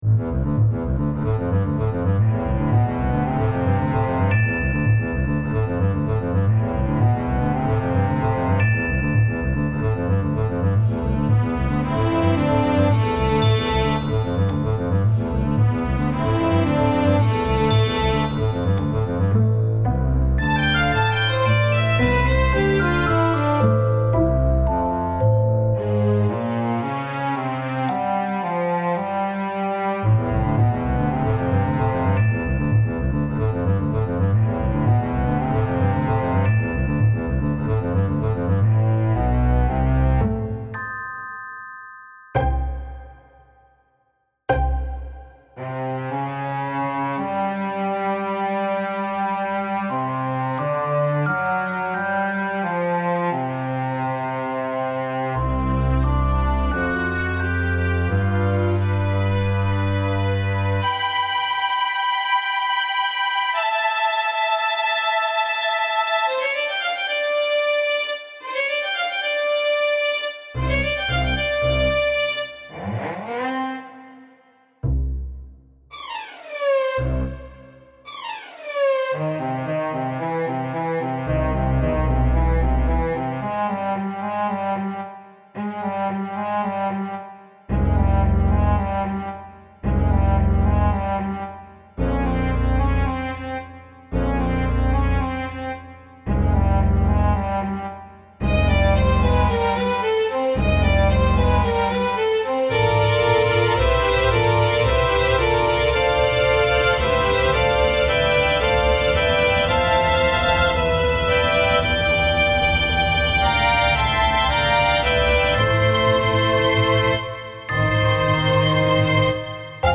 A Tone Poem in C Major for String Orchestra and Celesta
The music culminates in a pizzicato chord.